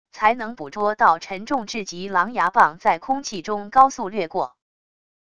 才能捕捉到沉重至极狼牙棒在空气中高速掠过wav音频